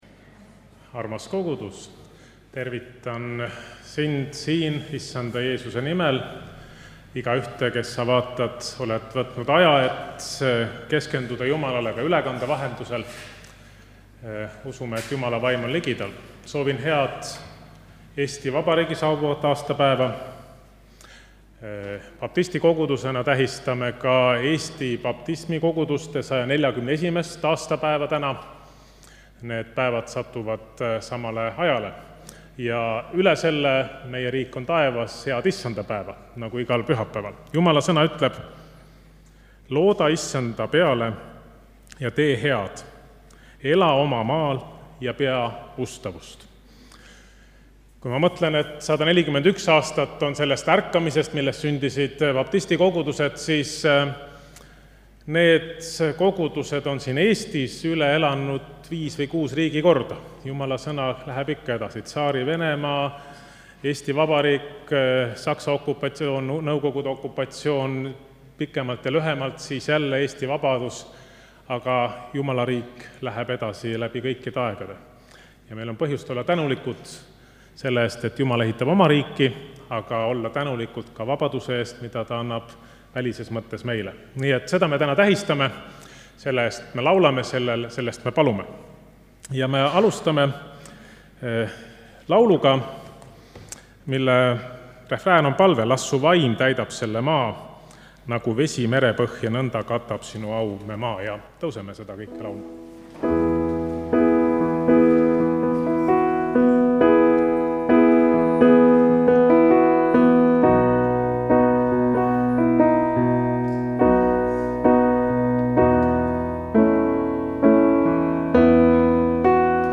Jutlus
Muusika: ansambel